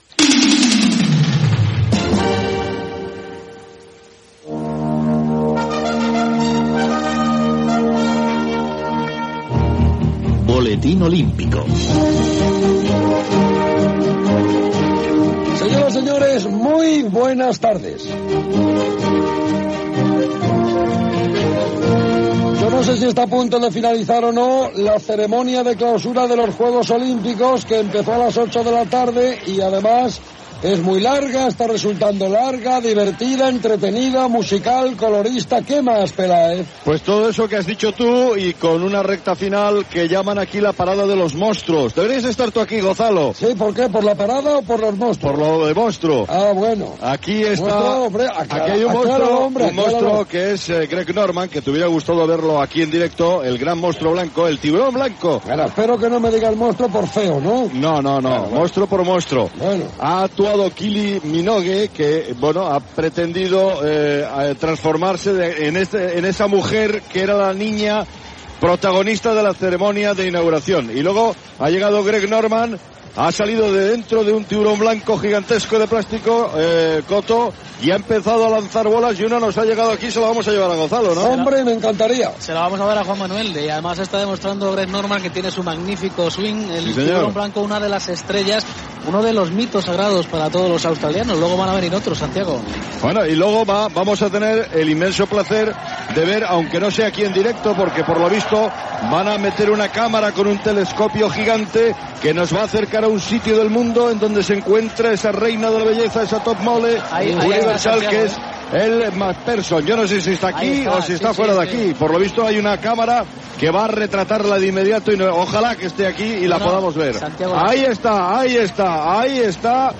Resultat de la selecció de waterpolo, amb declaracions de Manel Estiarte.
Comiat, careta de sortida i indiactiu de la ràdio
Esportiu